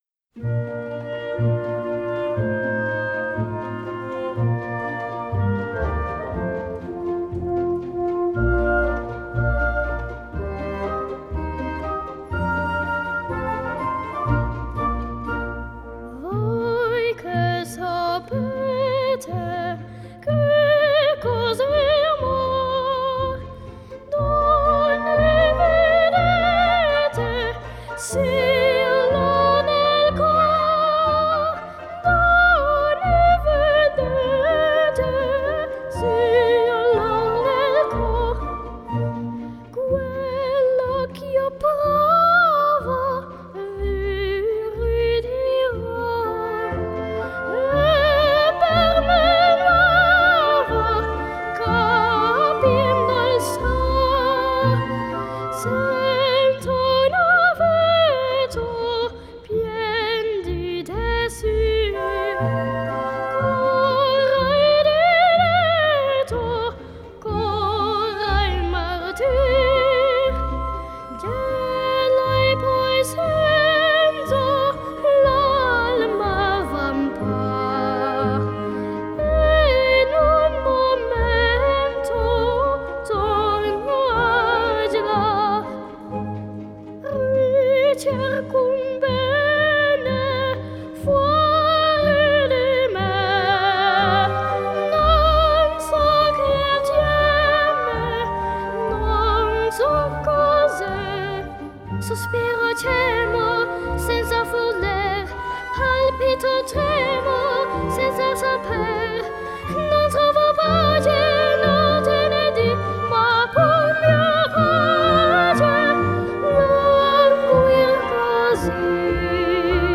Genre: Classical, Opera